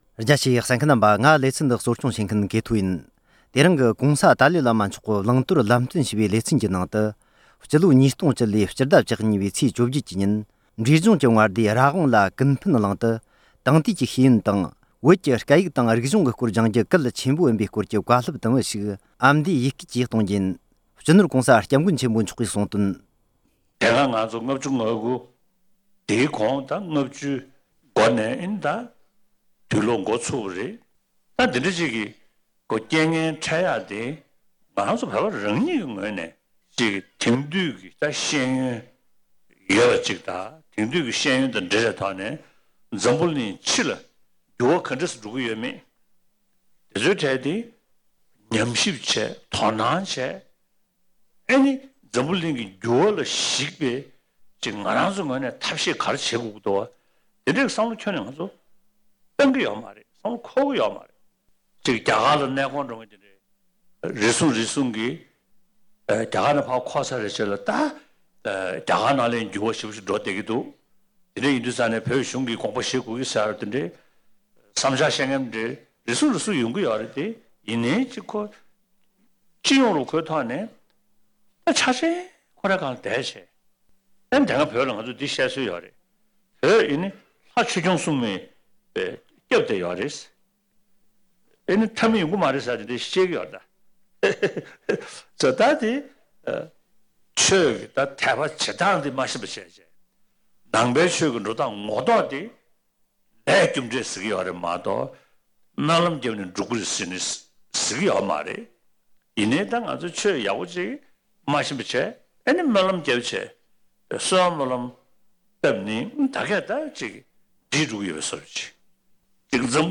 ༄༅། །འདི་རིང་གི་༧གོང་ས་མཆོག་གི་བླང་དོར་ལམ་སྟོན་ཞེས་པའི་ལེ་ཚན་ནང་། དེང་དུས་ཀྱི་ཤེས་ཡོན་དང་། བོད་ཀྱི་སྐད་ཡིག་དང་རིག་གཞུང་ཟུང་འབྲེལ་གྱིས་སྦྱང་བཙོན་བྱེད་དགོས་པའི་སྐོར་གྱི་བཀའ་སློབ་དུམ་བུ་ཞིག་ཀེ་ཐོས་ཨམ་སྐད་དུ་སྒྱུར་སྒྲིག་བྱས་པར་གསན་རོགས།